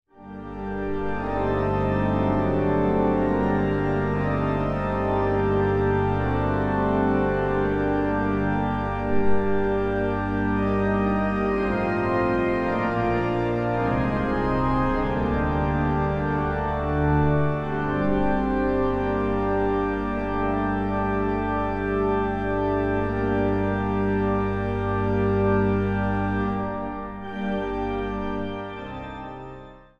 Meditatief orgelspel
vanuit de Grote of Sint Nicolaaskerk te Elburg